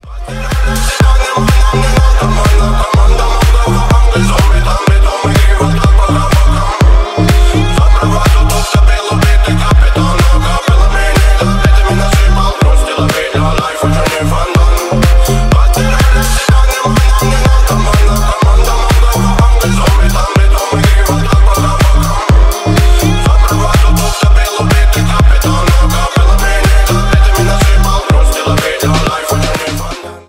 Ремикс # Поп Музыка
ритмичные # грустные